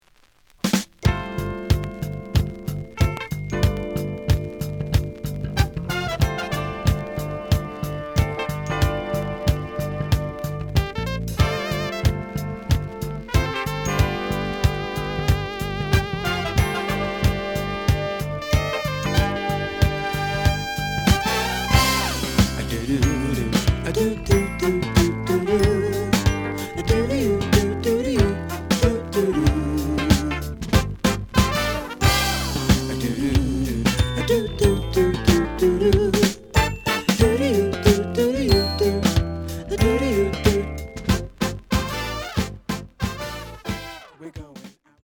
The audio sample is recorded from the actual item.
●Format: 7 inch
●Genre: Disco
Looks good, but slight noise on A side.